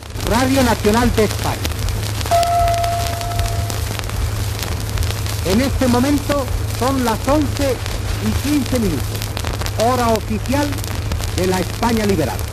Identificació de l'emissora i hora a la zona nacional ("de la España liberada")